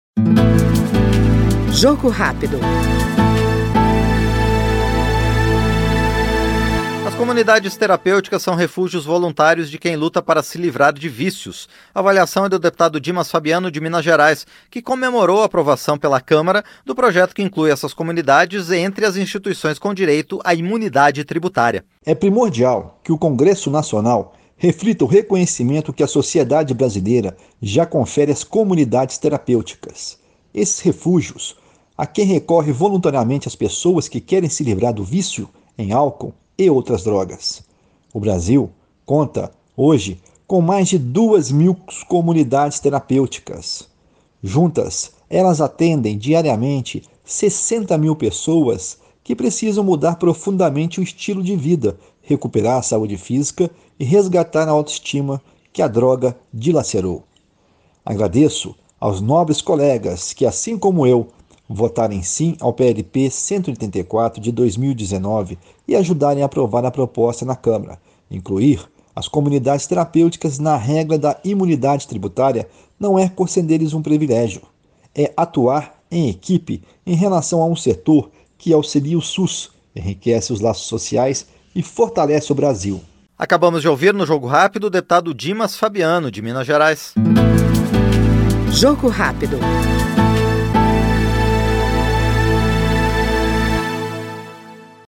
Jogo Rápido é o programa de entrevistas em que o parlamentar expõe seus projetos, sua atuação parlamentar e sua opinião sobre os temas em discussão na Câmara dos Deputados.